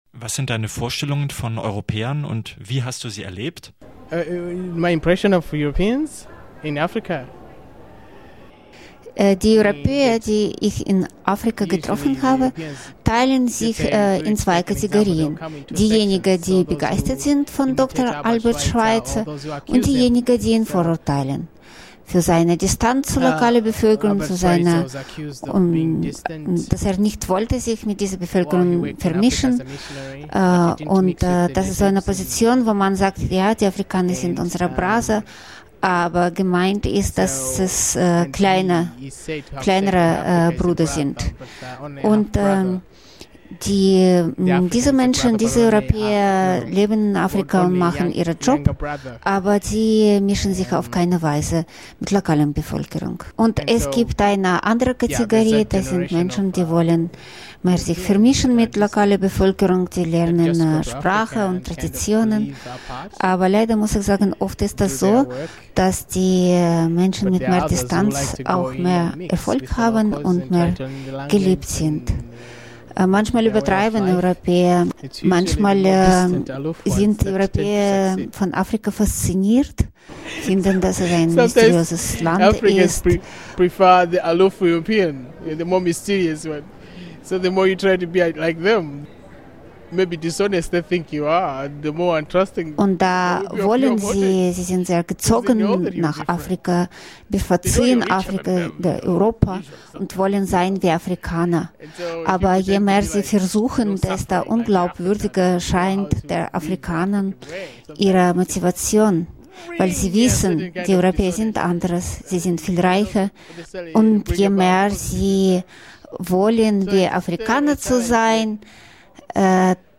Was ist an Europa eigentlich europäisch? - Ein Gespräch mit dem afrikanischen Künstler und Schriftsteller Samson Kambalu aus Malawi